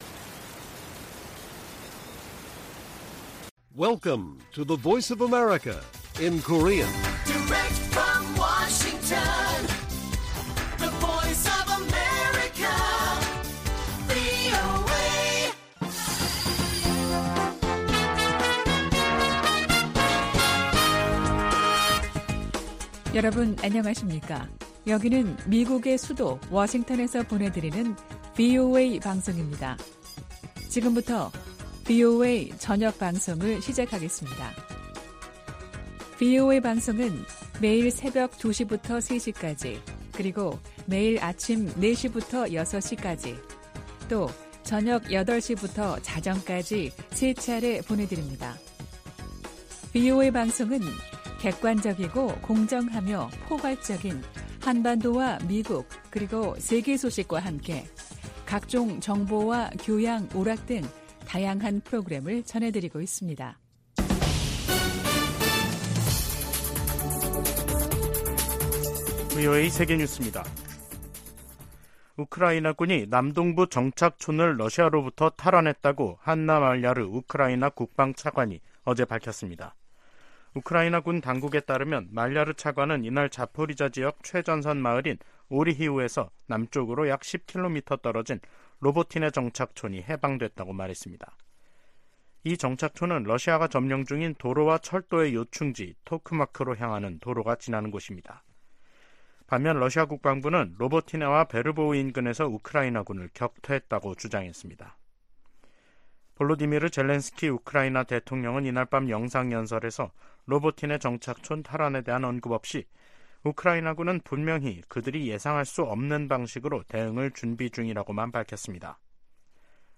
VOA 한국어 간판 뉴스 프로그램 '뉴스 투데이', 2023년 8월 29일 1부 방송입니다. 제네바에서 열린 유엔 회의에서 미국은 북한의 위성 발사가 전 세계에 대한 위협이라고 비판했습니다. 북한이 위성 발사 실패시 미국과 한국이 잔해를 수거 분석할 것을 우려해 의도적으로 로켓을 폭파시켰을 가능성이 있다고 전문가들이 분석했습니다. 김정은 북한 국무위원장이 미한일 정상들을 비난하면서 3국 합동훈련 정례화 등 합의에 경계심을 드러냈습니다.